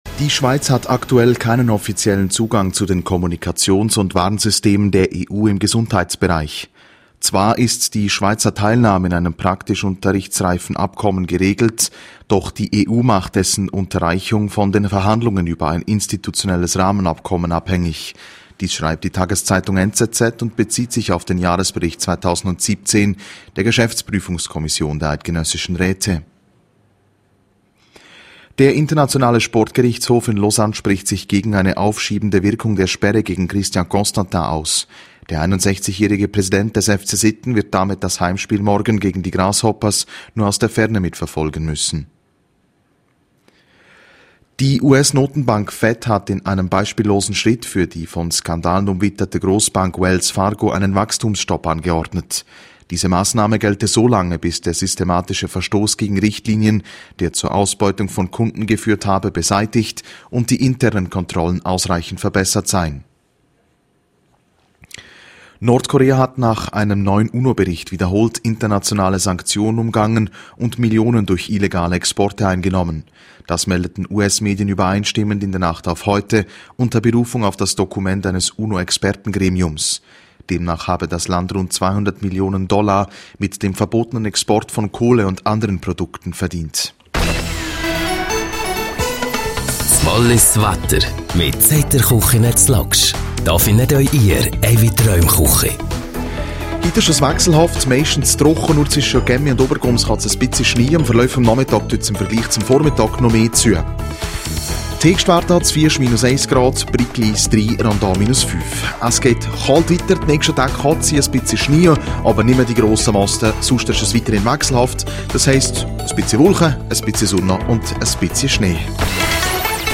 12:30 Uhr Nachrichten (2.45MB)